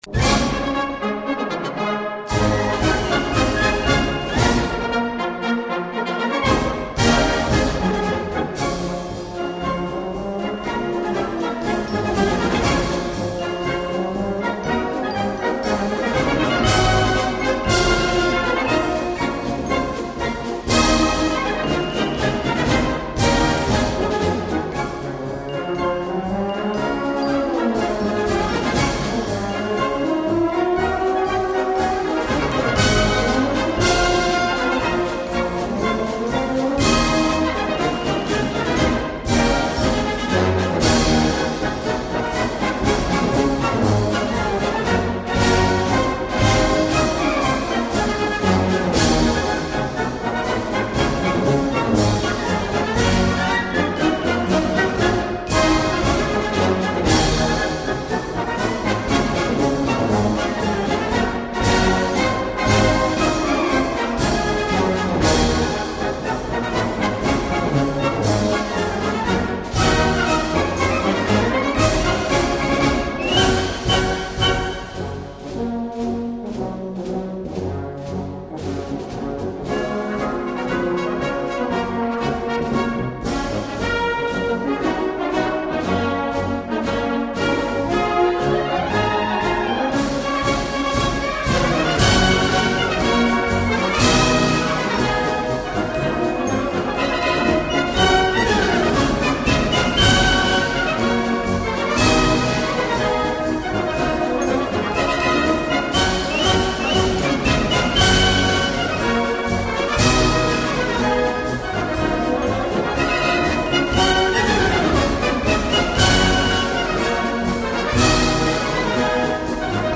Марш праздник победы!!